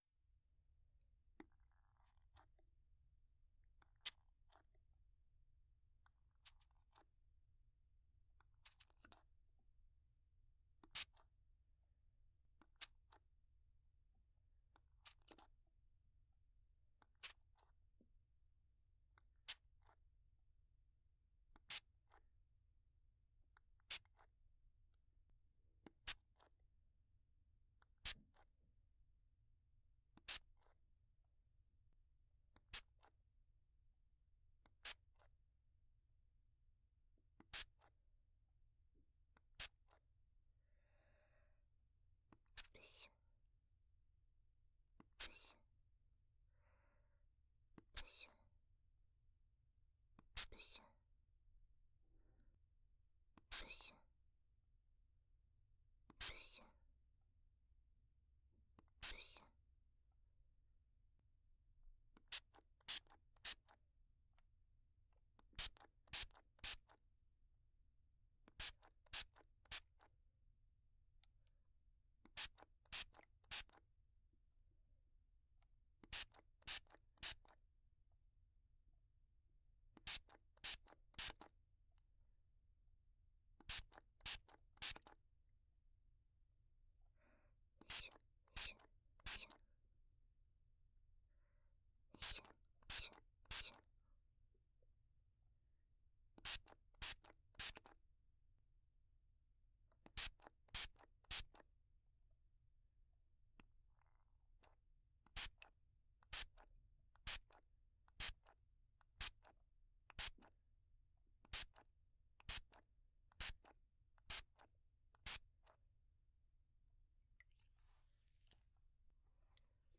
02.水音パートのみ.wav